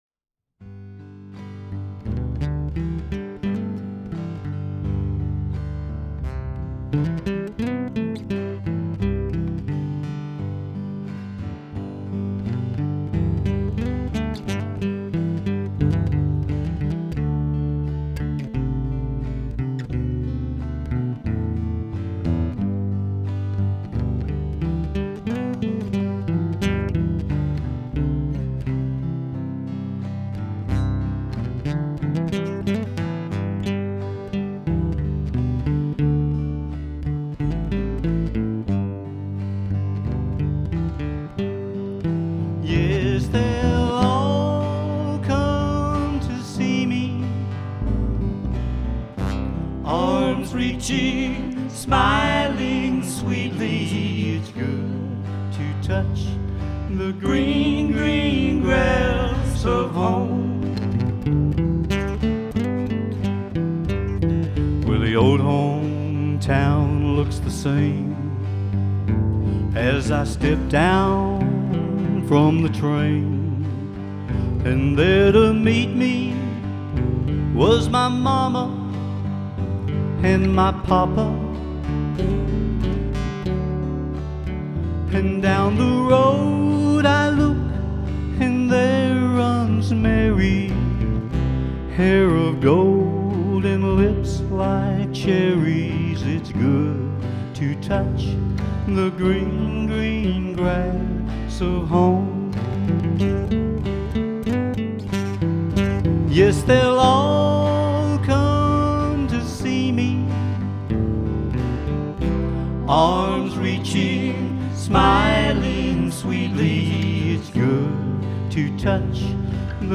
Traditional acoustic music, live and in person.